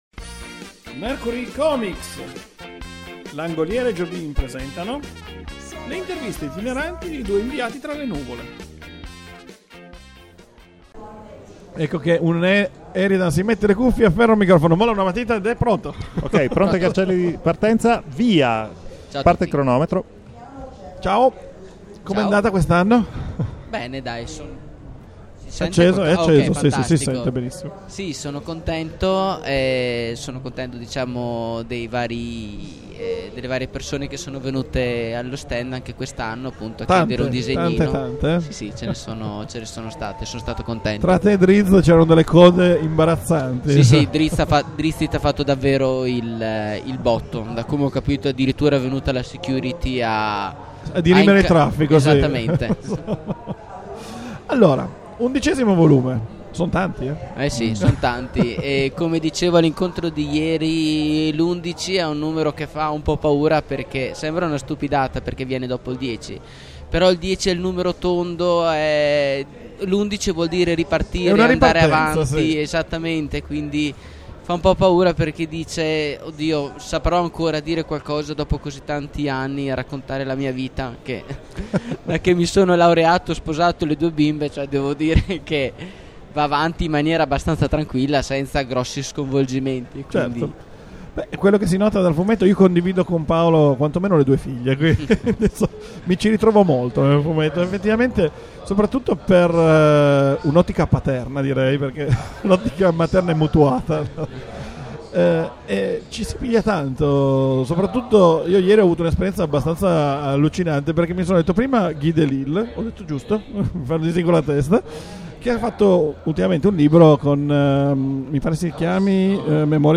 LUCCA C&G 2013 – Sabato: TERZO GIORNO DI DIRETTA
Nuuuu…. il diluvio universale imperversa fuori dai padiglioni affollati!